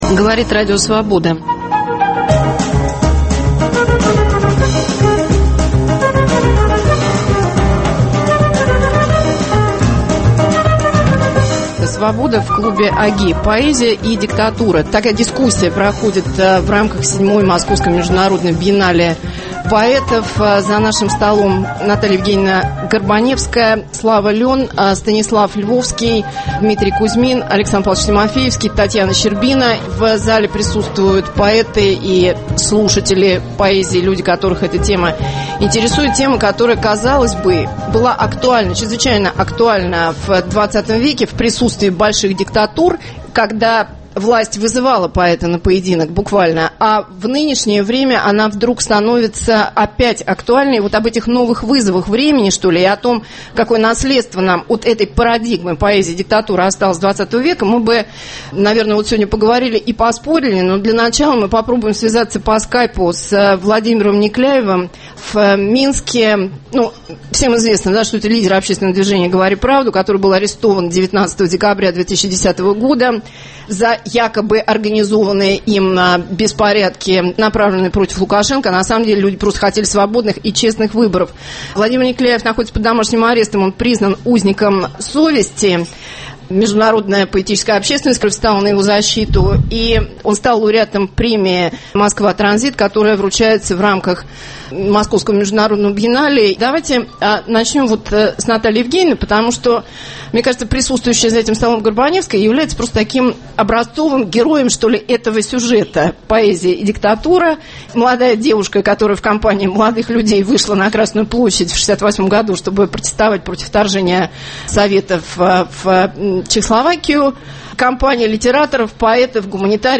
Свобода в клубе «ОГИ». Поэзия и диктатура.